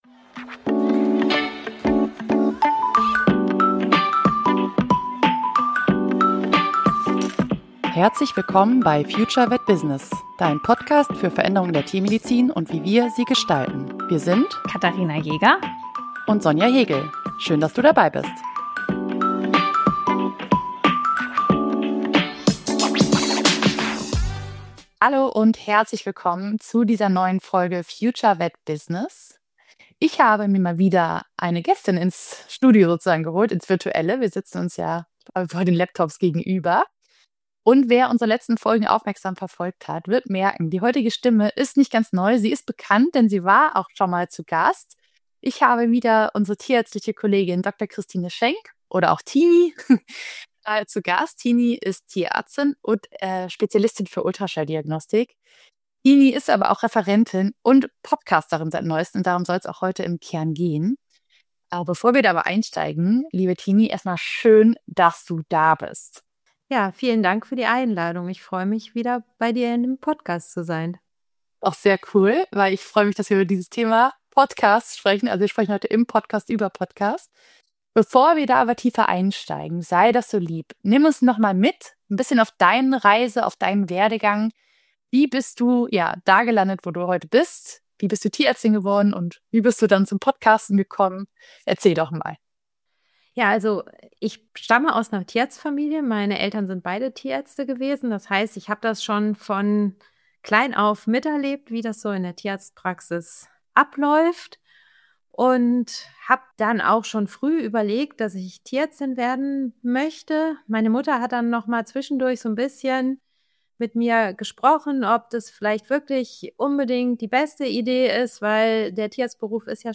Interview ~ FutureVetBusiness Podcast